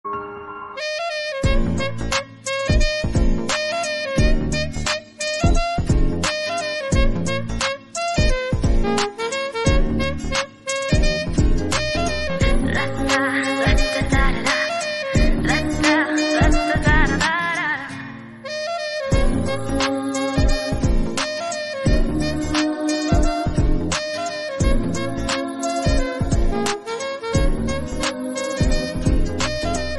melody tamil instrumental